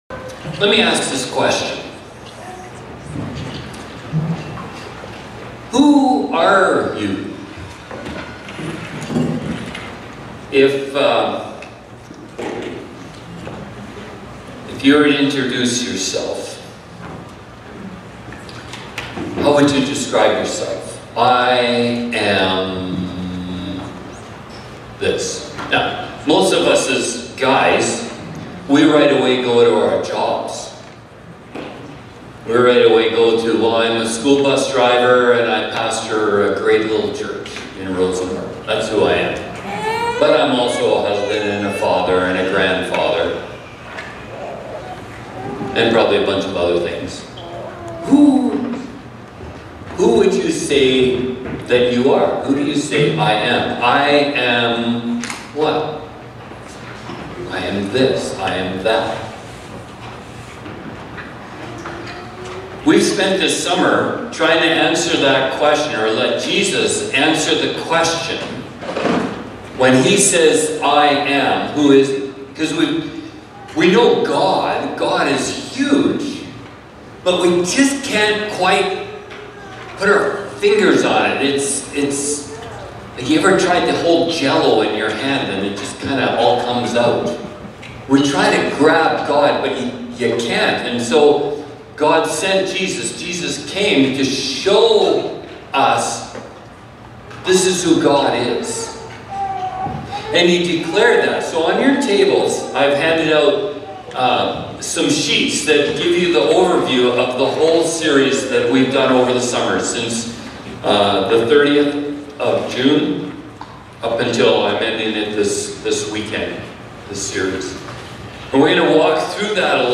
Sermons | Rosenort Community Church